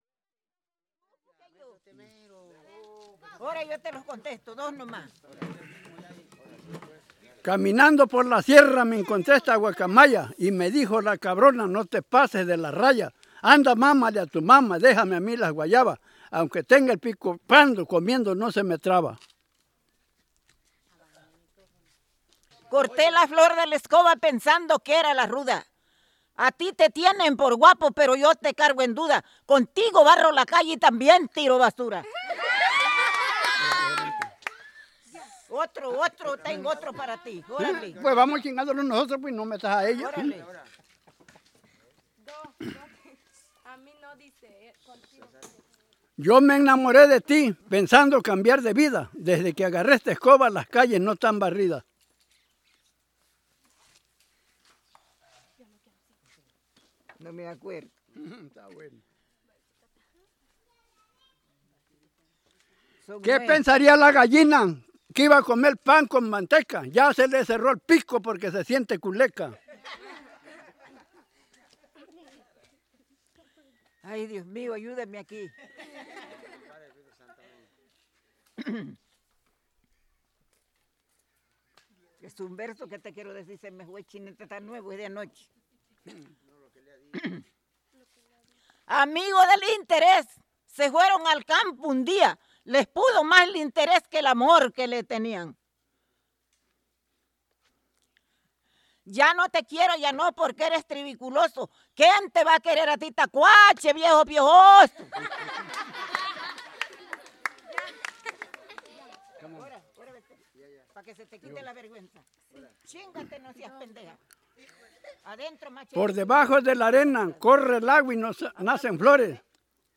Versos, música y baile de Artesa de la Costa Chica: San Nicolás, Guerrero y el Ciruelo, Oaxaca